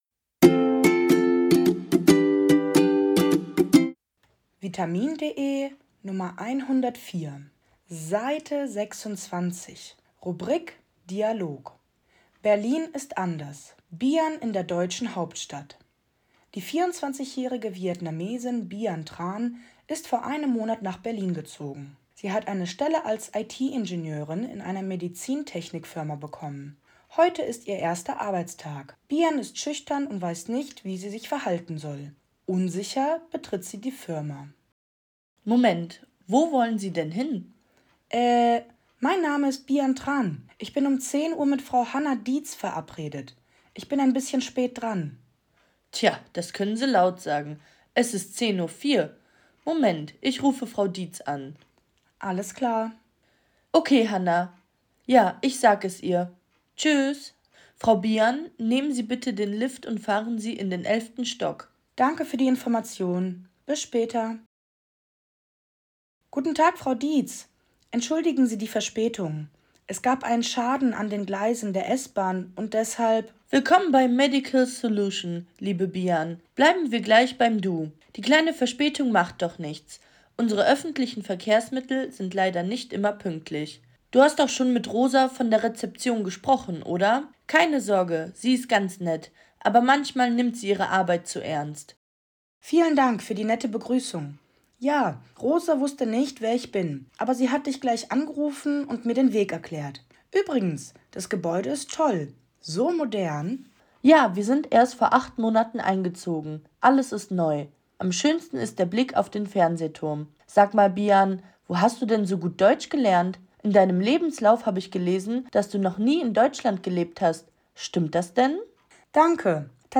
Audiodatei (Hörversion) zum Text
vde_104_Dialog_Berlin.mp3